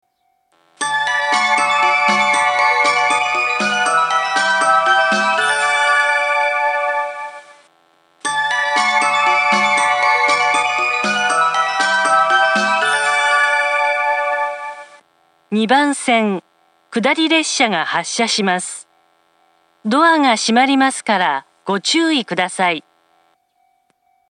列車の本数が少ないので駅員放送が入ることが多く、メロディー・放送に被ることも多いです。
２番線下り発車メロディー